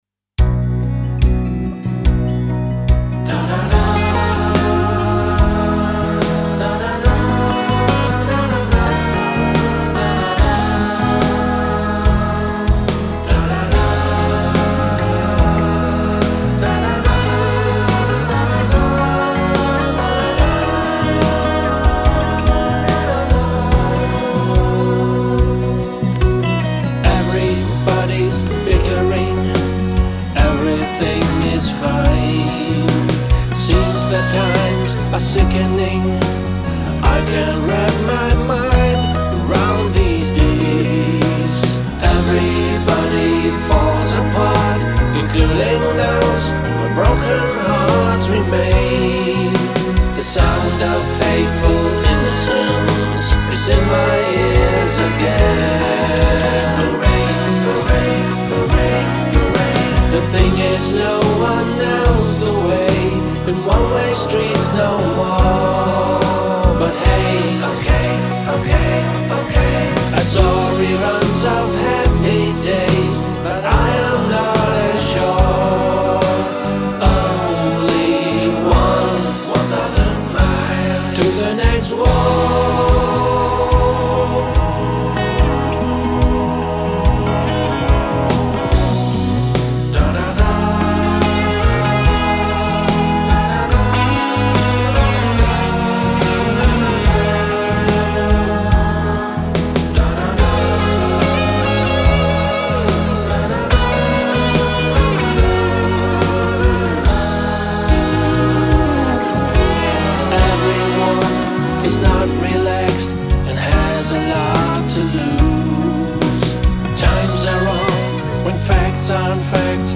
Pop-Powerballade mixen - Bass und Gitarren
Ich habe da diesen Song, eine Powerballade (vermutlich im Tempo 72, aufgenommen aber im halben Tempo mit 144 bpm).